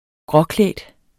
Udtale [ ˈgʁʌˌklεˀd ]